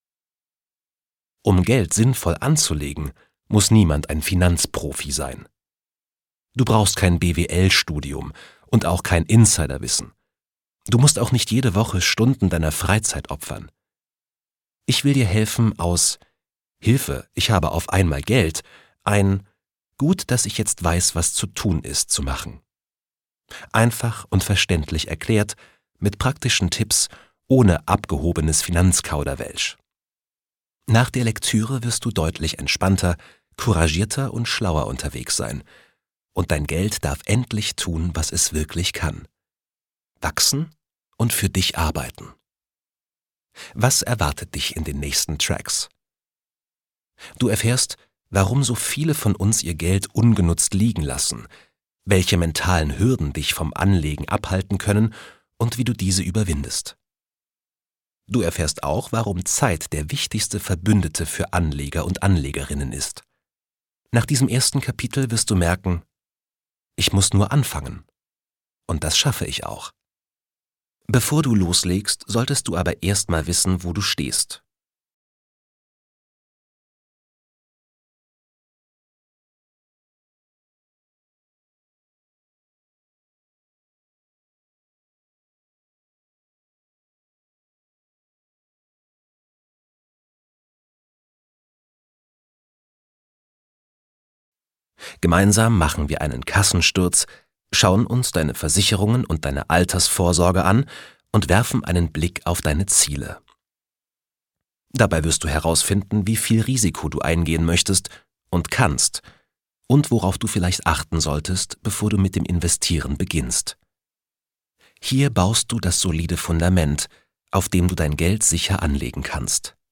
Ein Hörbuch, die sich im wahrsten Sinne des Wortes lohnt.
Gekürzt Autorisierte, d.h. von Autor:innen und / oder Verlagen freigegebene, bearbeitete Fassung.